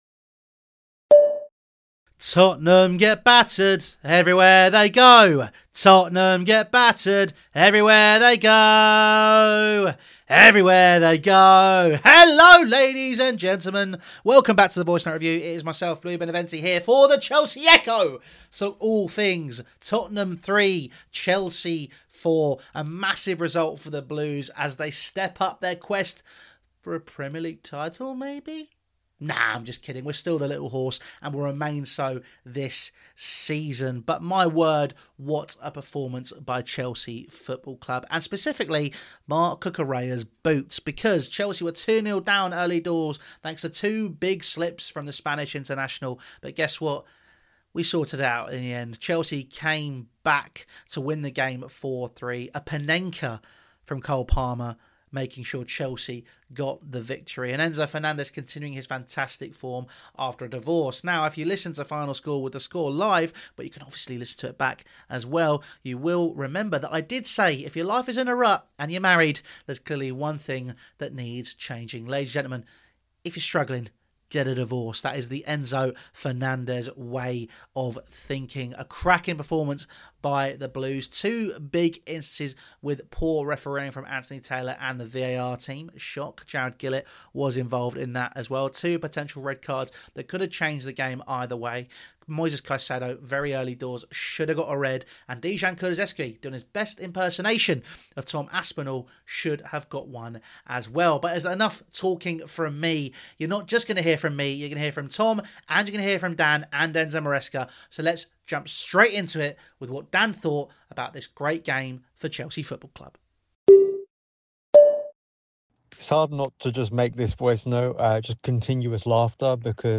If your life is in a rut, get a divorce... | Tottenham 3-4 Chelsea | Voicenote Review